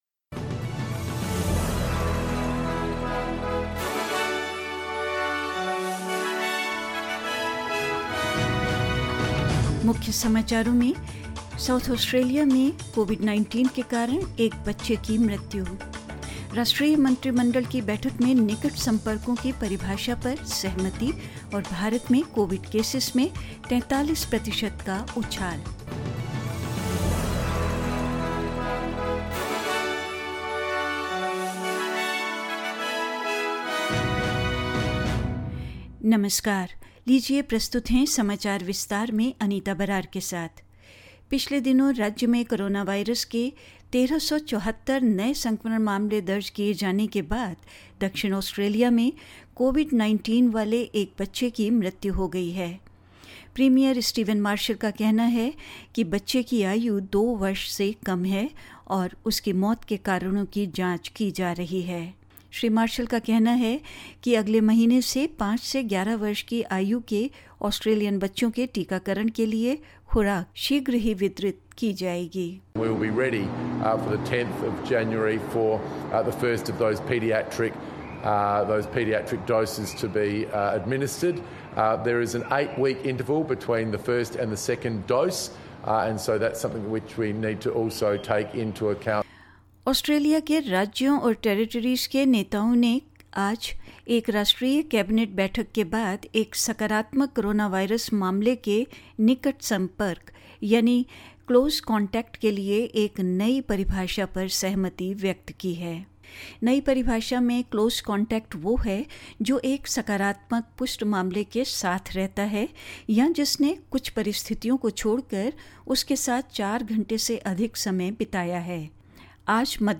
In this latest SBS Hindi news bulletin: A child with coronavirus dies in South Australia; National Cabinet agrees to redefine the meaning of "close contact"; India reports 43 per cent jump in coronavirus cases and more news.